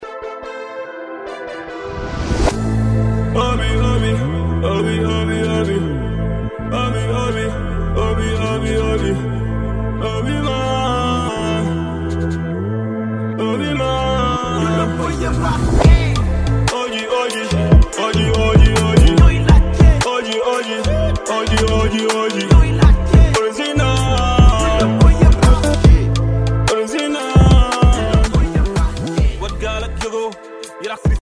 Un album 100% Rap mêlant émotions, poésie et mélodies